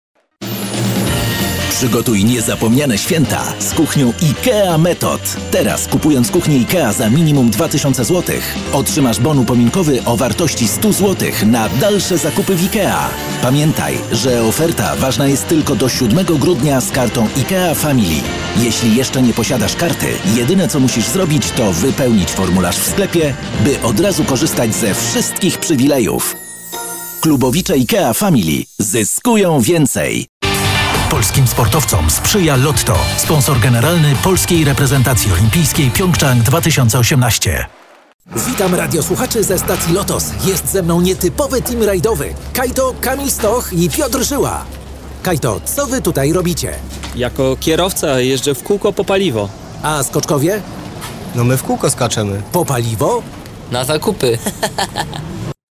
Znani i lubiani Mężczyzna 30-50 lat
Nagranie lektorskie